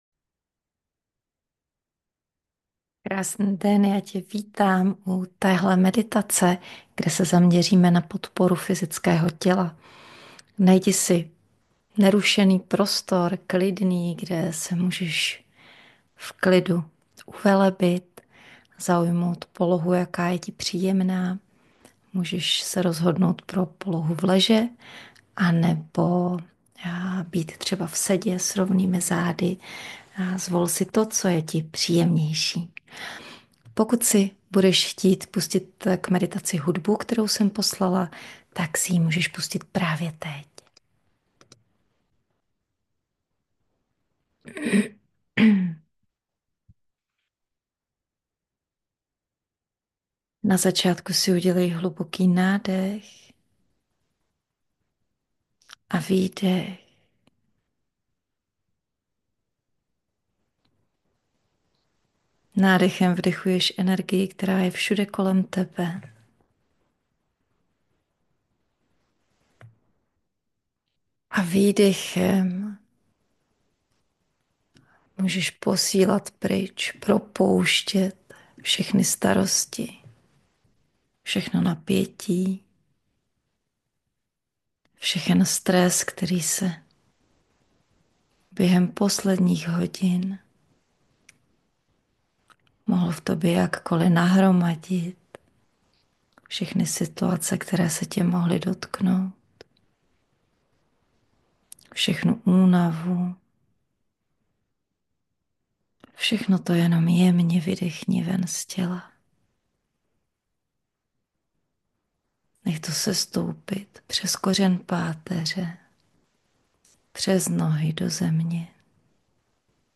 Na začátku meditace vás včas upozorním , kdy si hudbu pustit .